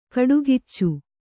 ♪ kaḍugiccu